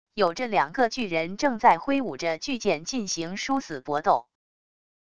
有着两个巨人正在挥舞着巨剑进行殊死搏斗wav音频